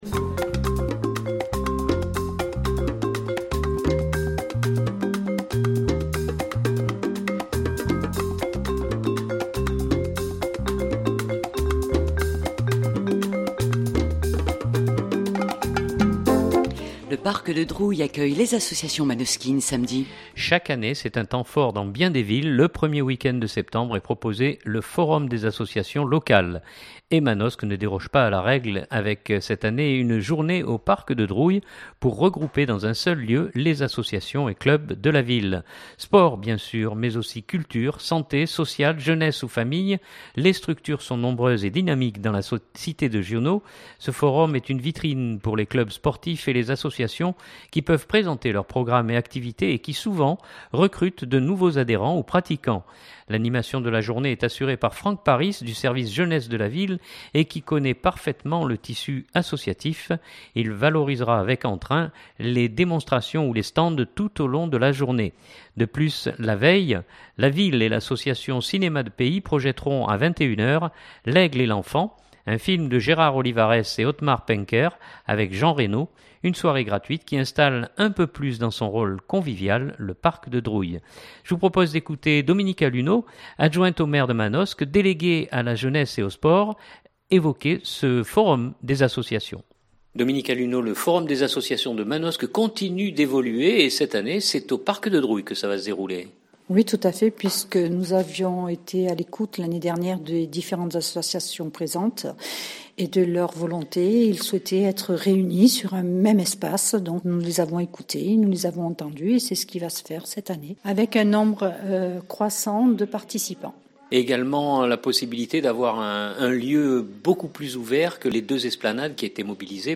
Je vous propose d’écouter Dominique Alunno, adjointe au maire, déléguée à la jeunesse et aux sports évoquer ce forum des associations.